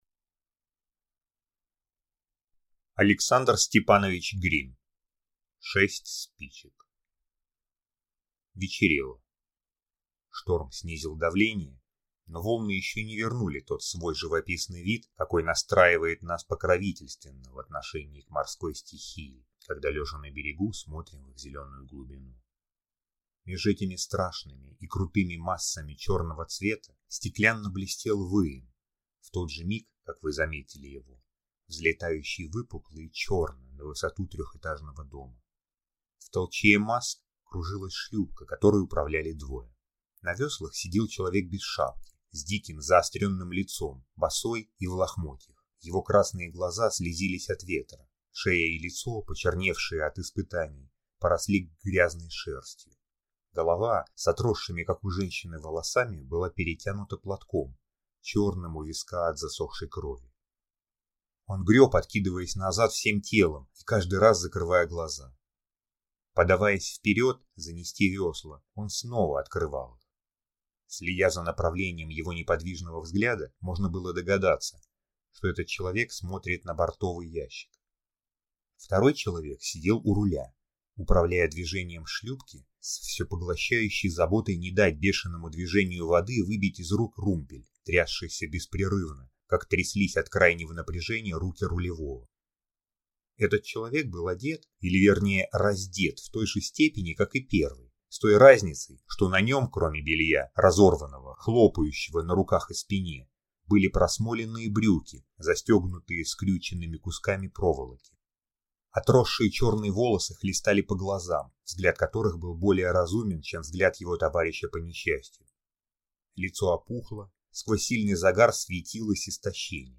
Аудиокнига Шесть спичек | Библиотека аудиокниг